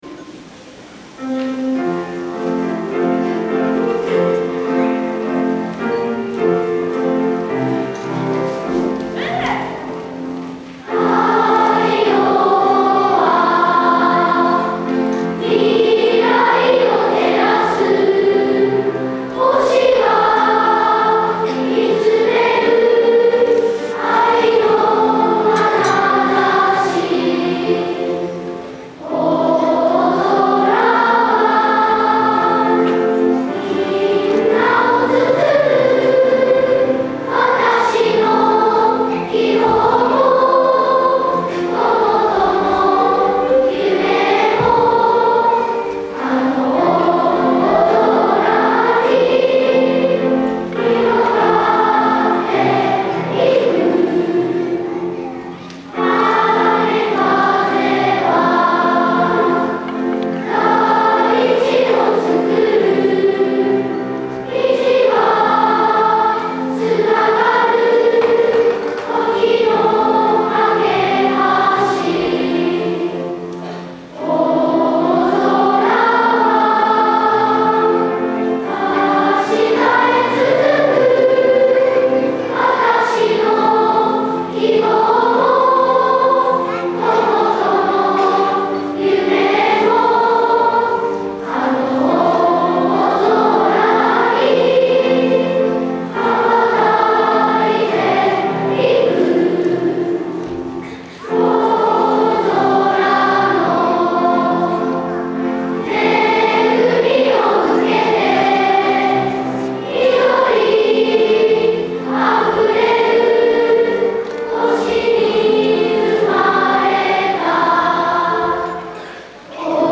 校歌」会場2部合唱です。
みんなが大切にしている校歌を歌い、会場のみんなで歌声を合わせます♪